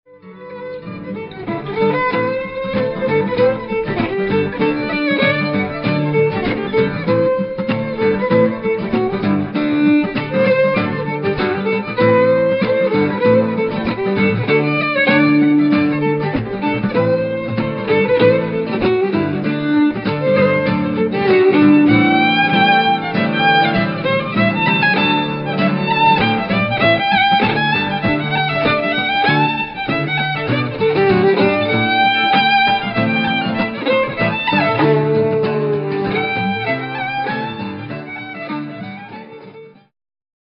Fiddle, Banjo, Guitar, Percussion, Bass.